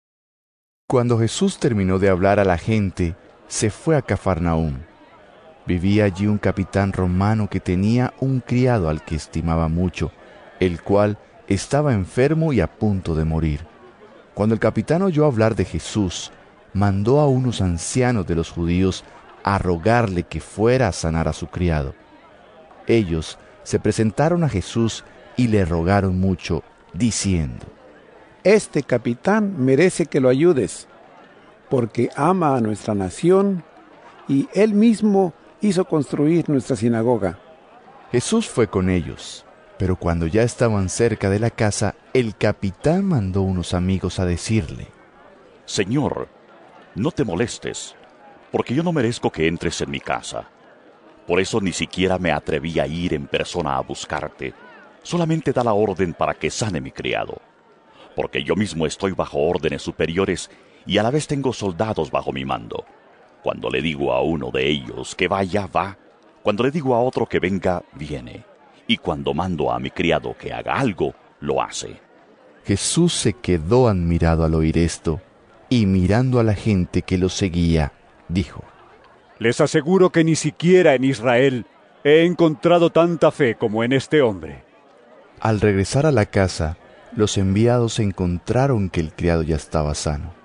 Lc 7 1-10 EVANGELIO EN AUDIO